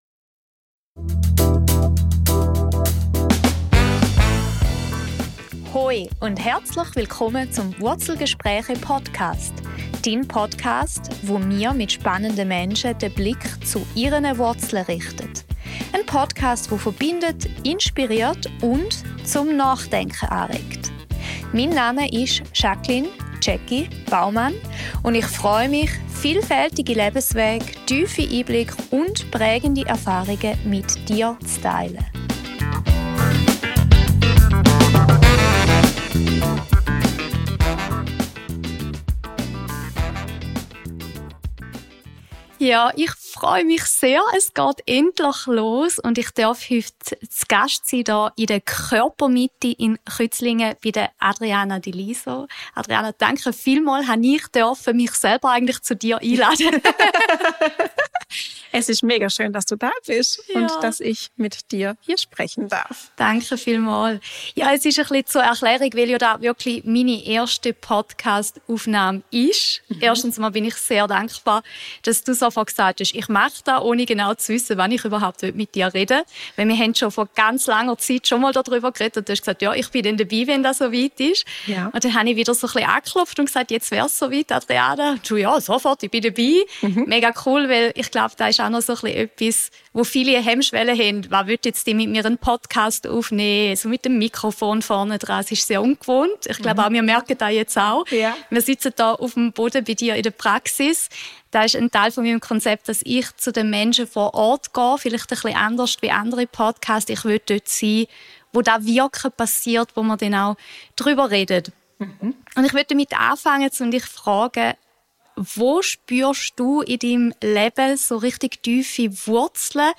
Ein aufschlussreiches Gespräch, das Denkanstöße für mehr Selbstfürsorge, Heilung und Verbindung zu uns selbst liefert.